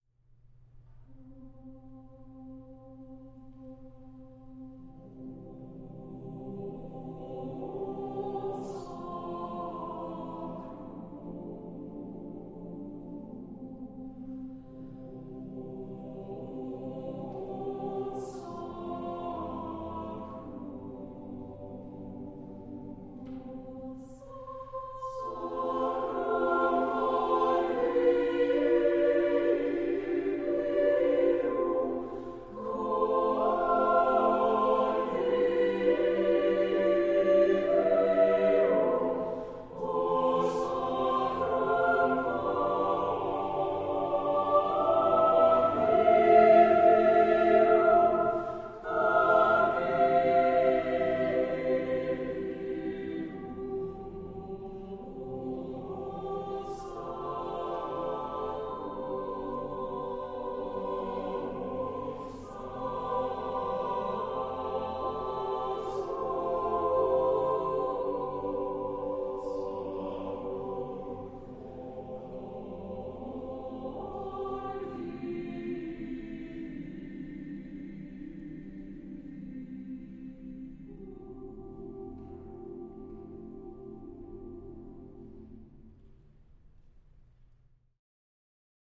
one of the premier choral ensembles in the country.